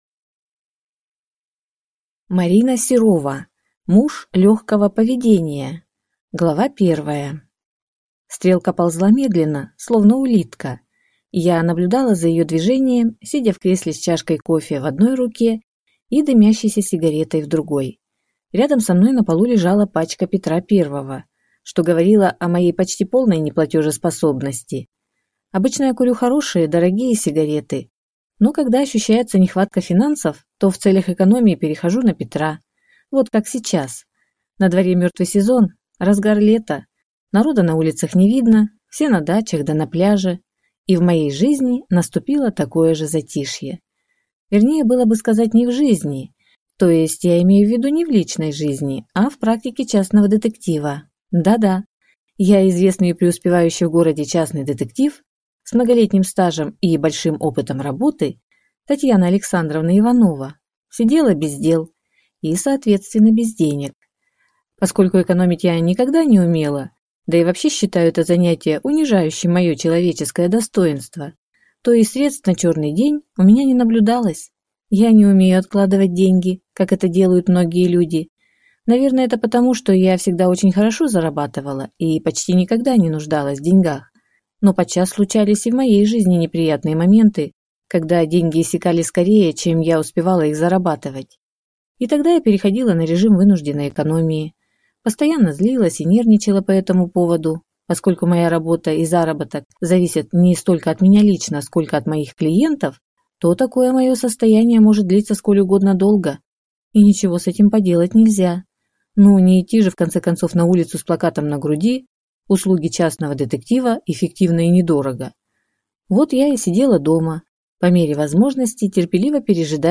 Аудиокнига Муж легкого поведения | Библиотека аудиокниг